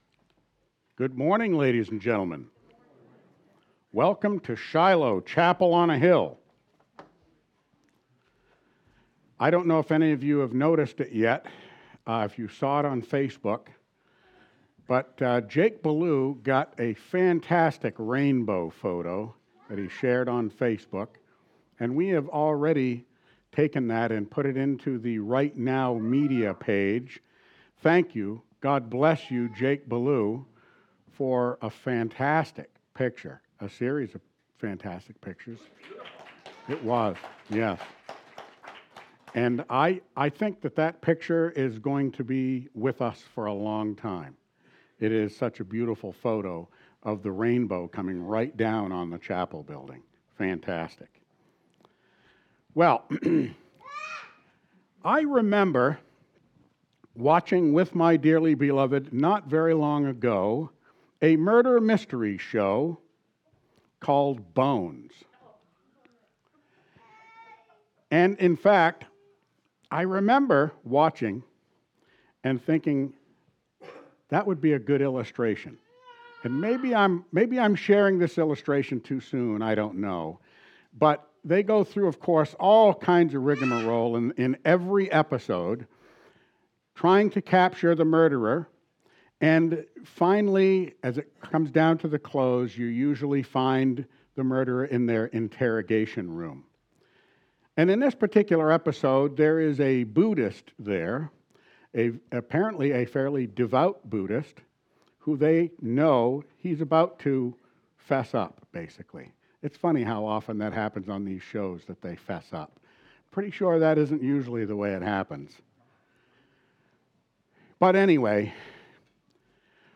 Sunday, October 20, 2024 Worship Service: Acts Part 33 “The First Church Council”